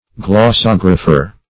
Search Result for " glossographer" : The Collaborative International Dictionary of English v.0.48: Glossographer \Glos"sog"ra*pher\, n. [Gr.
glossographer.mp3